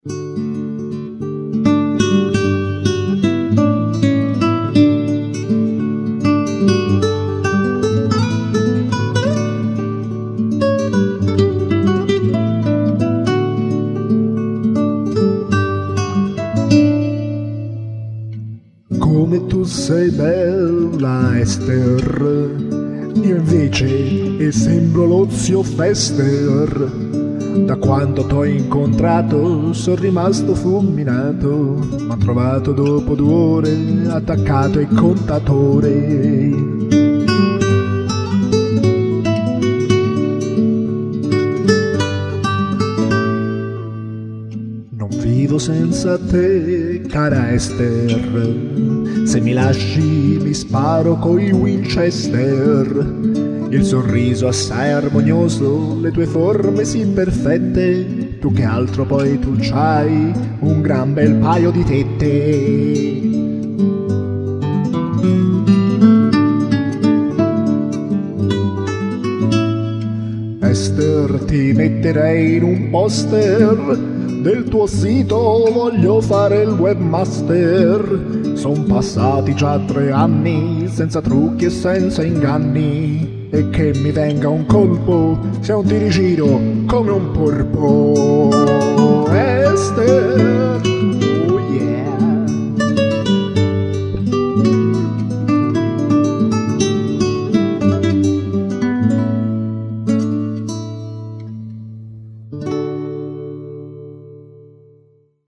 Una serenata